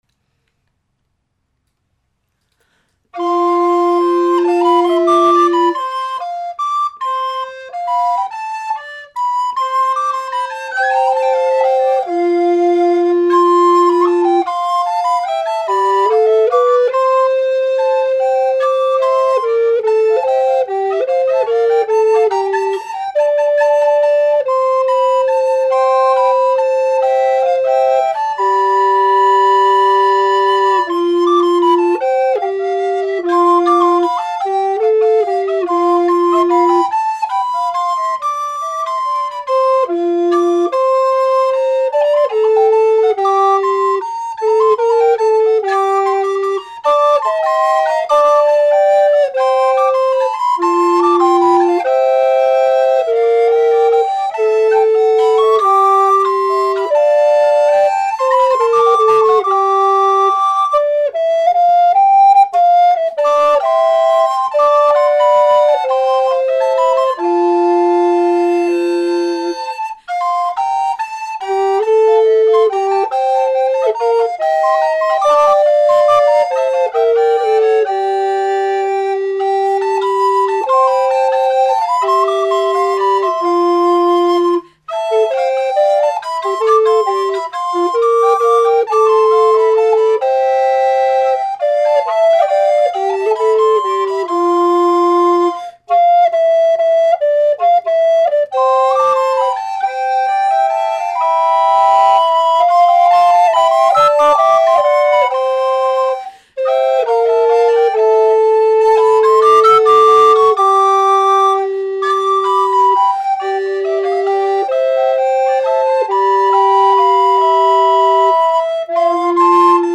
From the 5 November 2006 concert, La Caccia